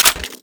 weap_sml_gndrop_4.wav